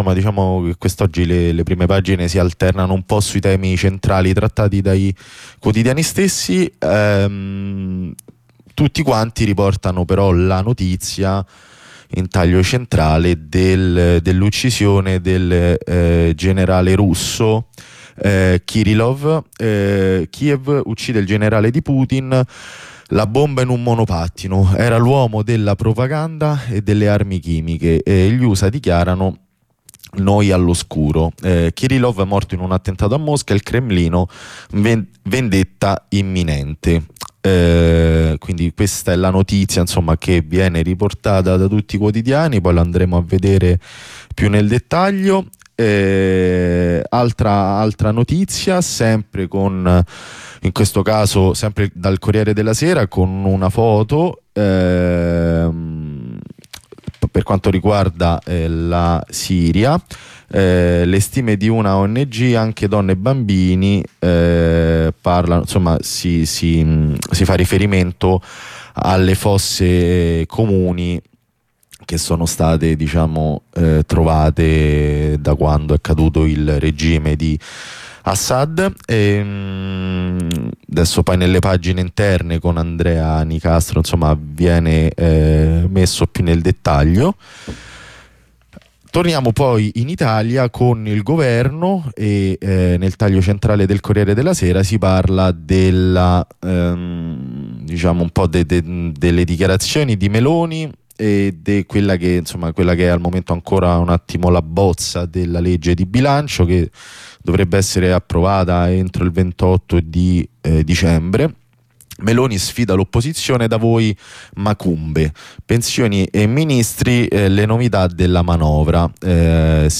Rassegna stampa Mercoledì 18 Dicembre
Lettura delle prime pagine e pagine interne dei giornali nazionali e delle prime pagine degli internazionali. Durante la mattinata abbiamo avuto anche due collegamenti in diretta dal porto di Genova per la morte di un lavoratore e da compagne alla Leonardo spa di Tiburtina.